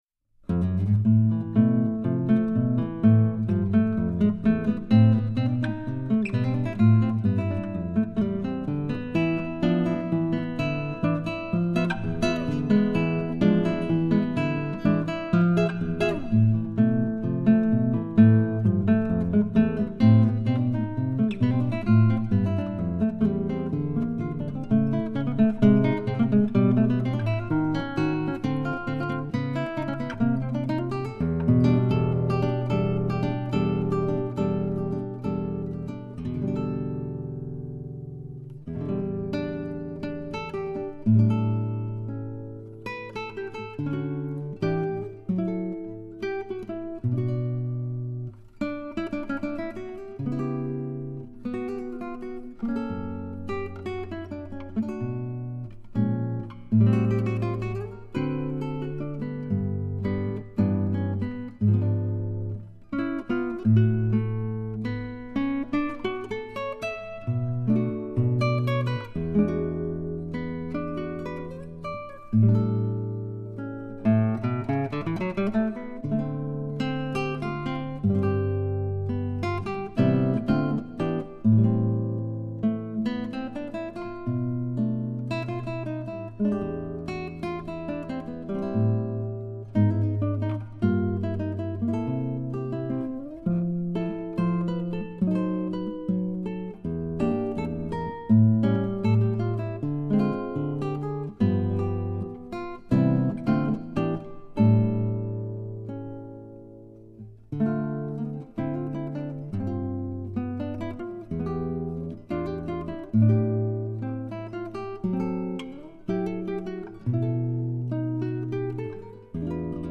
(Tangos y una Guitarra)
Guitarra y Arreglos